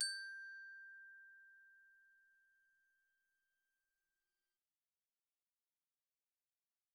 glock_medium_G5.wav